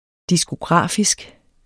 Udtale [ disgoˈgʁɑˀfisg ]